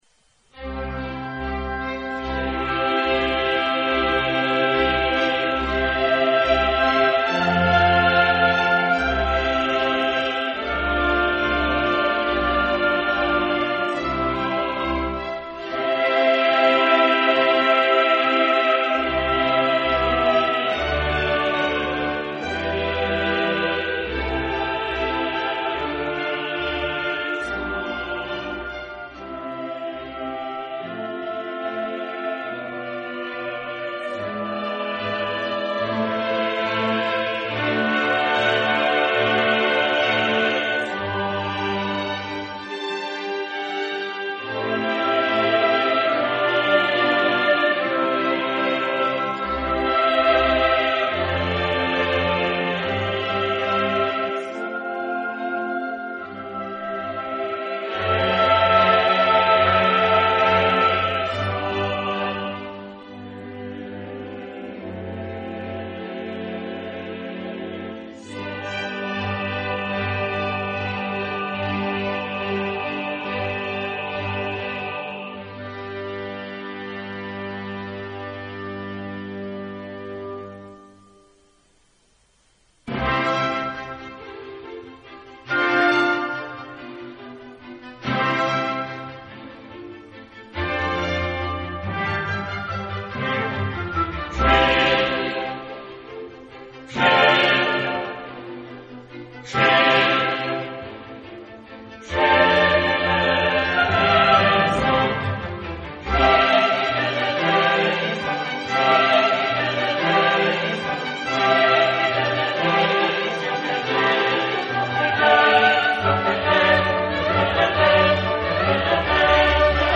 Genre-Style-Forme : Sacré ; Classique ; Messe
Type de choeur : SATB  (4 voix mixtes )
Solistes : SATB  (4 soliste(s))
Tonalité : do majeur